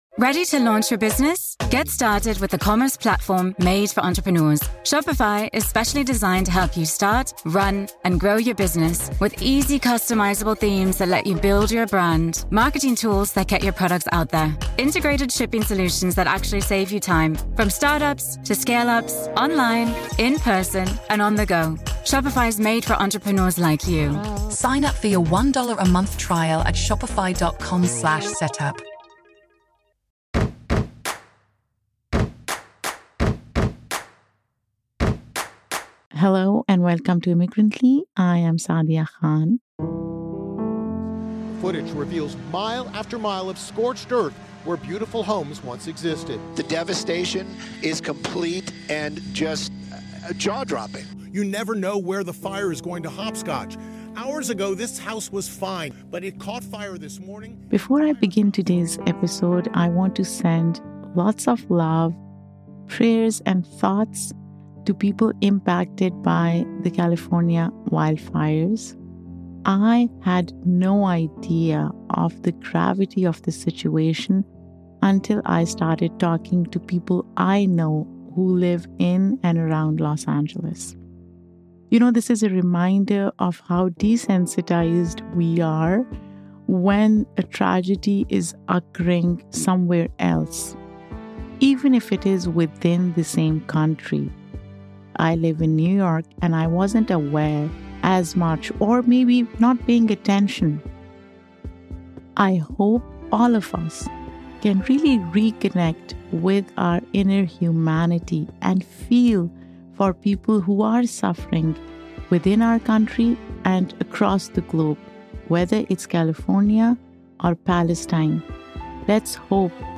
When a Christian Minister and a Muslim Podcaster Walk Into a Studio…